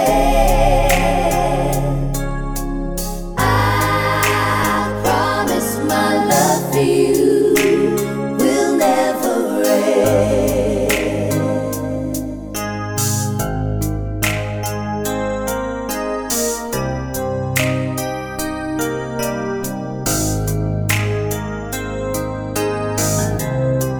no Backing Vocals Soul / Motown 3:10 Buy £1.50